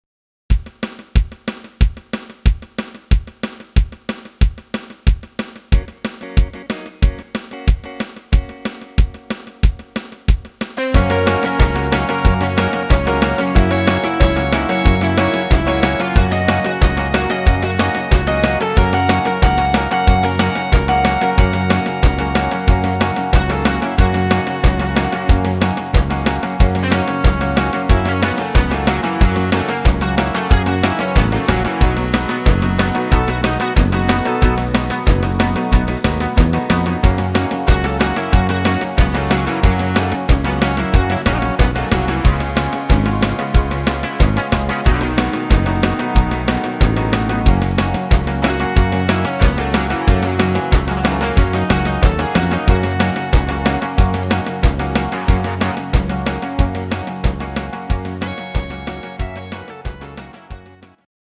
Rock'n Roll Standard